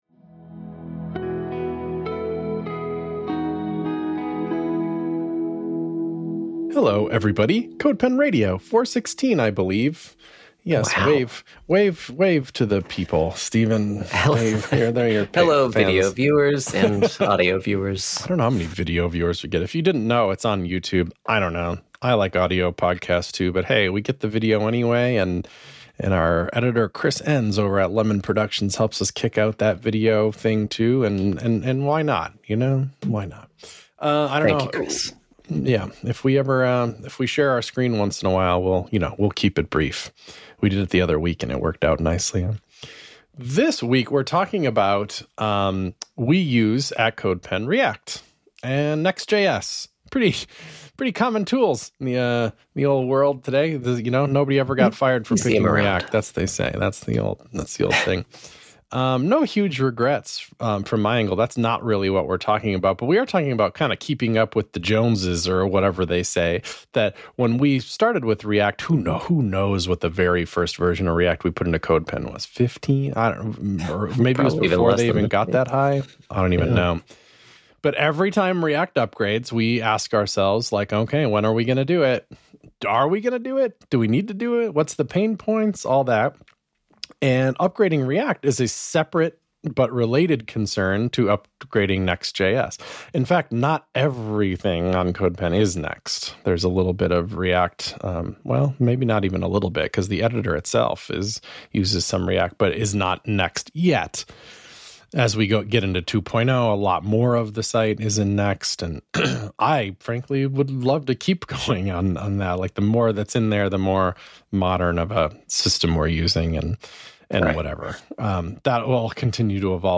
The CodePen team talk about the ins and outs of running a web software business.